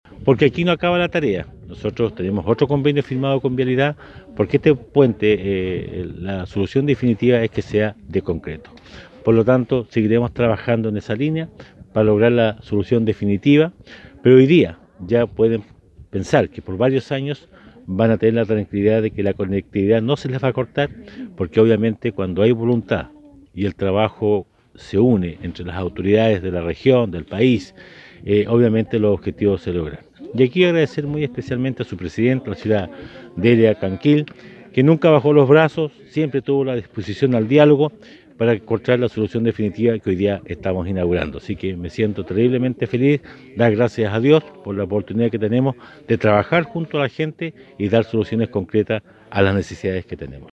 Durante el pasado fin de semana se realizó la ceremonia de inauguración de la reposición del Puente San Francisco, un anhelado proyecto por parte de los vecinos del sector distante a 8 kilómetros de Osorno por la Ruta 5 hacia el norte.
Del mismo modo el jefe comunal señaló que esta es una solución provisoria, pues en conjunto a la Dirección de Vialidad de Los Lagos se trabajará en el puente definitivo.